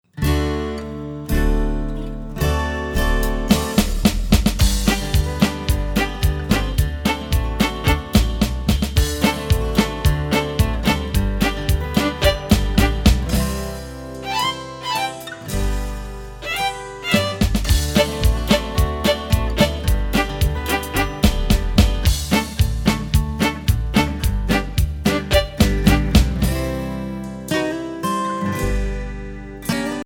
SOCIAL EMOTIONAL INSTRUMENTAL TRACKS